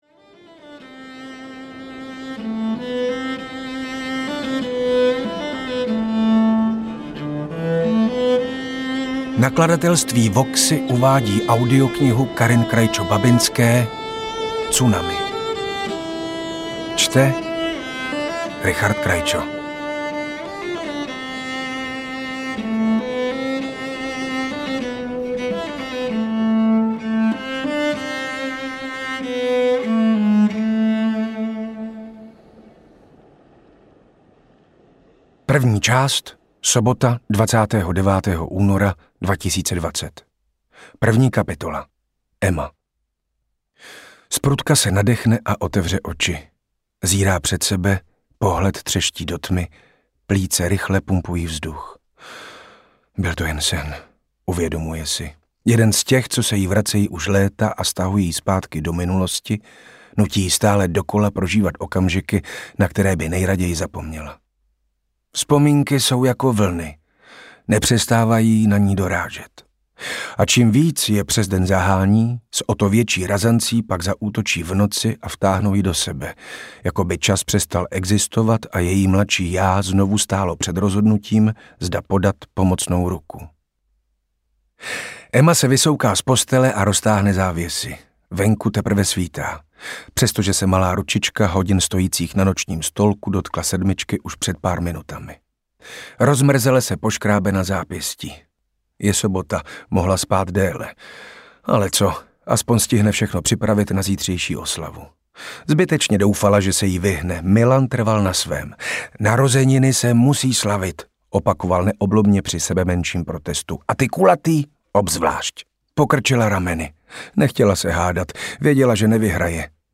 Audiokniha Tsunami, kterou napsala Karin Krajčo Babinská a načetl Richard Krajčo.
Ukázka z knihy